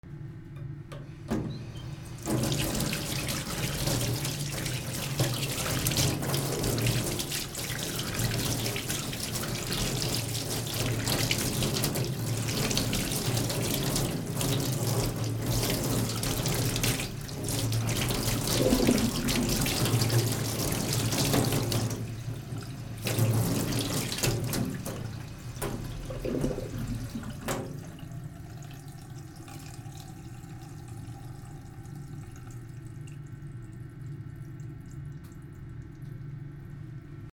手を洗う 台所シンク
/ M｜他分類 / L30 ｜水音-その他